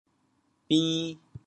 How to say the words 扁 in Teochew？
bin2.mp3